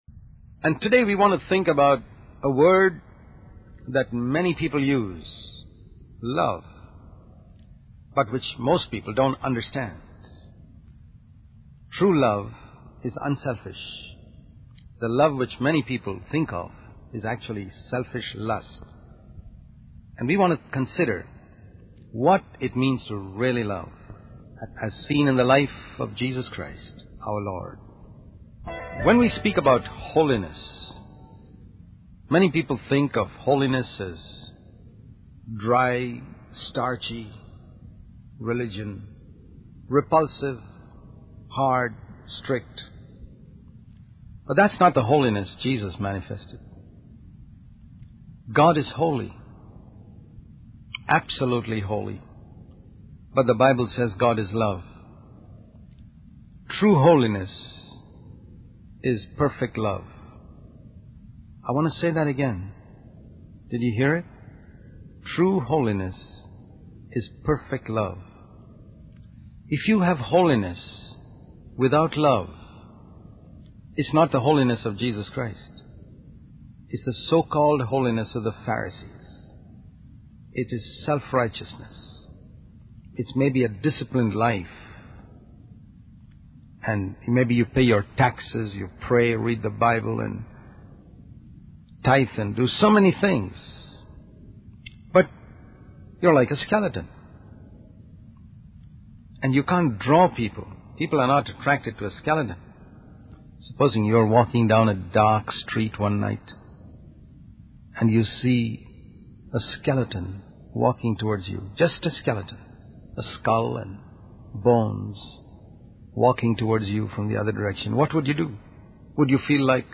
In this sermon, the speaker emphasizes the importance of balancing grace and truth in our lives, using the example of Jesus as the perfect model.